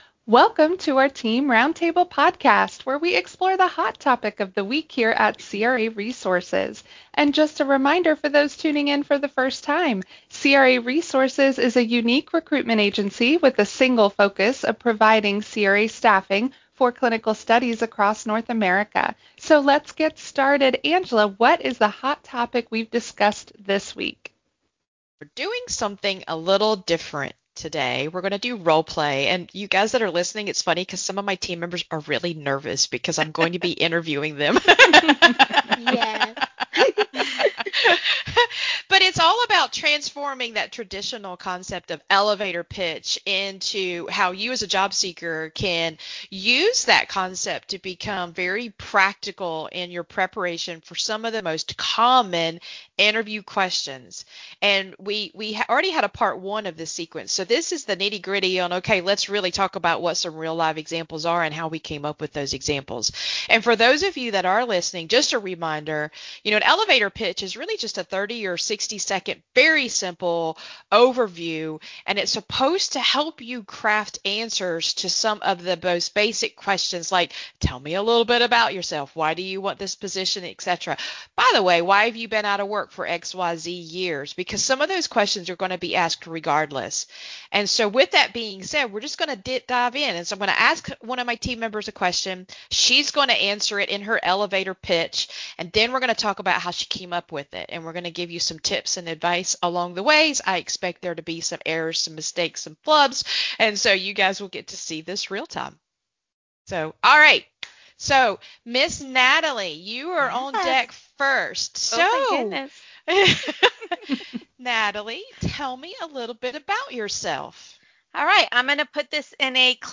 Roundtable: Job Seeker Elevator Pitch Examples - craresources
In this roundtable conversation, our coaching team takes a deep dive into real job seeker elevator pitch examples that go far beyond the rehearsed, one-size-fits-all approach you might find online.